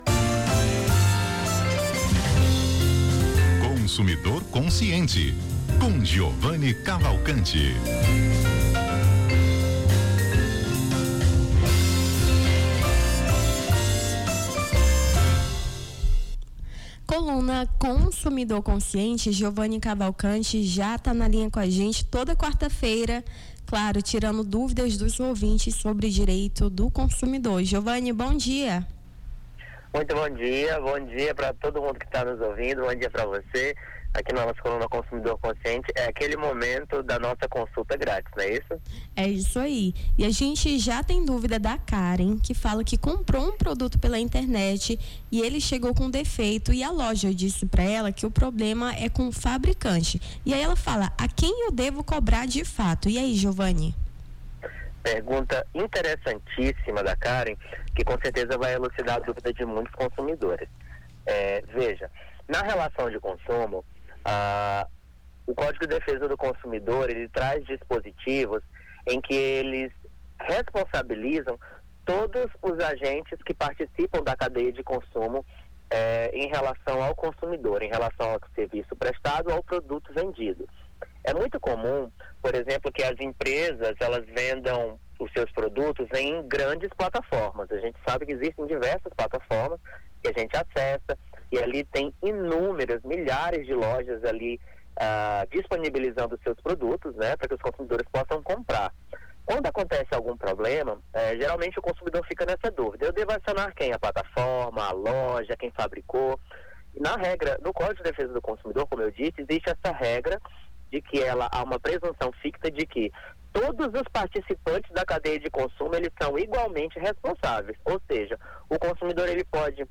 Consumidor Consciente: advogado tira dúvidas sobre compras online